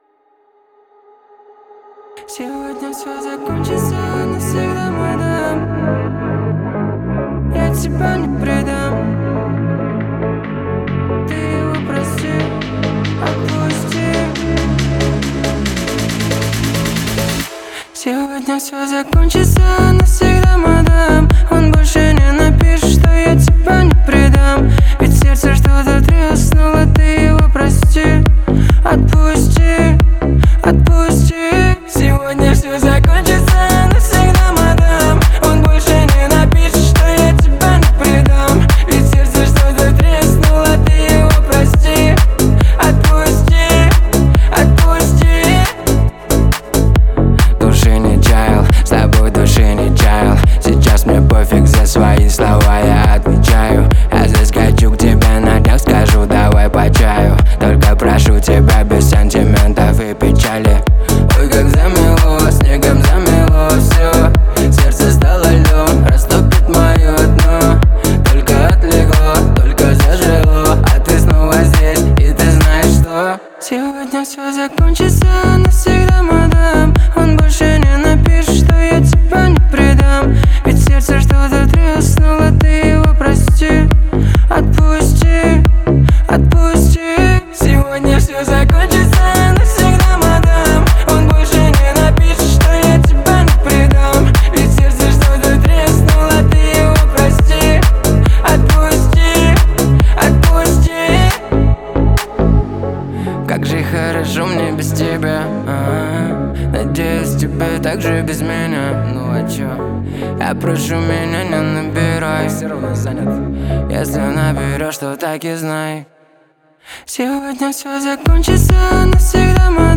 Танцевальная музыка
танцевальные песни